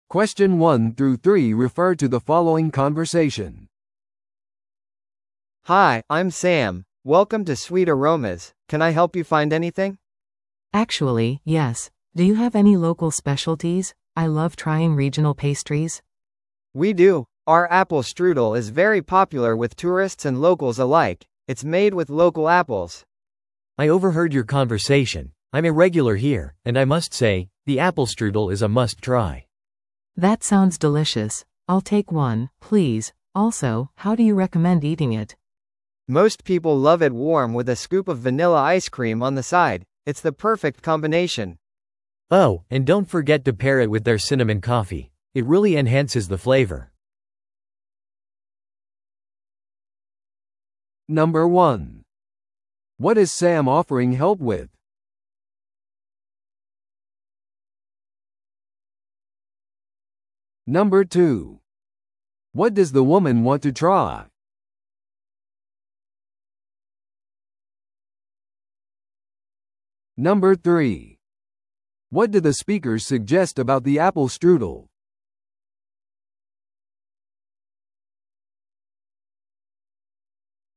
No.2. What does the woman want to try?
No.3. What do the speakers suggest about the apple strudel?